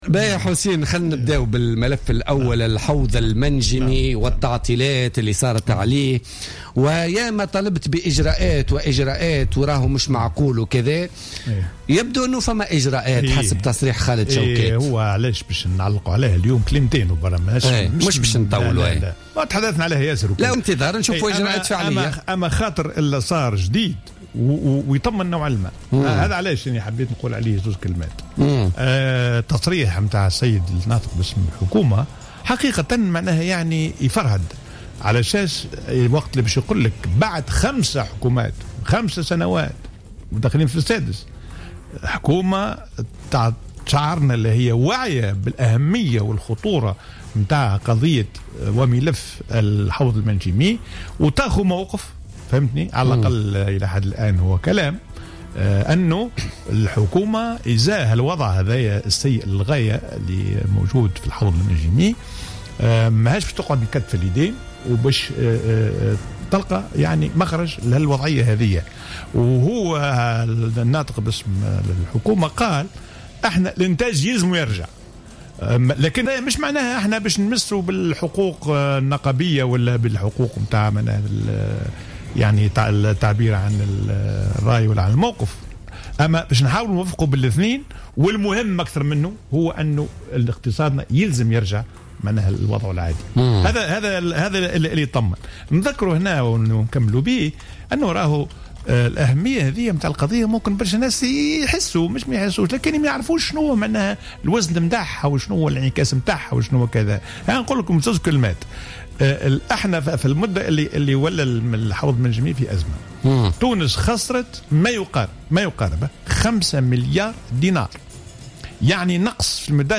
قال حسين الديماسي الخبير الاقتصادي ووزير المالية السابق في تصريح للجوهرة أف أم في برنامج بوليتكا لليوم الجمعة 25 مارس 2016 إن أزمة الحوض المنجمي وتوقف الانتاج كلف تونس خسارة بـ5 آلاف مليون دينار وهو ما يمثل حجم ميزانية التنمية لسنة كاملة.